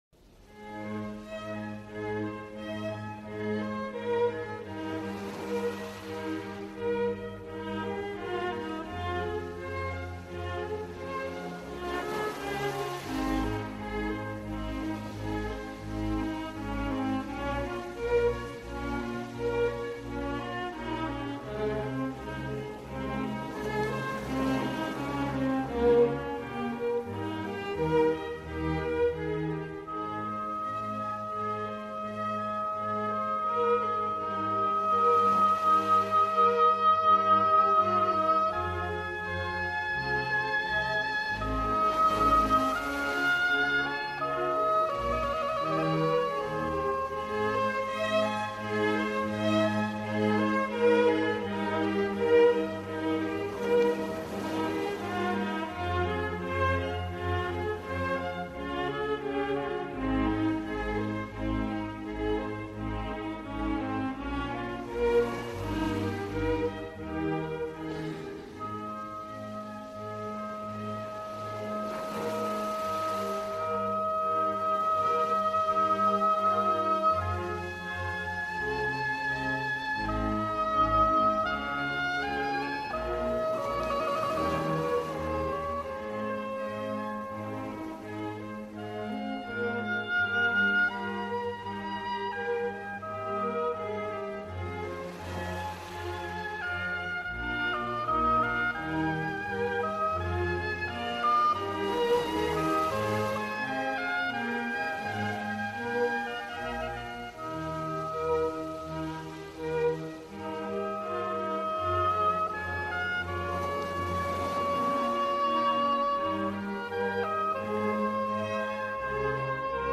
Venise Paisible : Canal Pluvieux